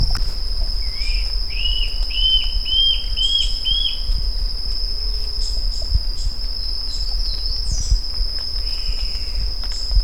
TUFTED ANTSHRIKE Mackenziaena severa ATLANTIC FOREST
Mackenziaena severa
Song variation recorded PROCOSARA, PN San Rafael
Mackenziaenaseverathroaty.wav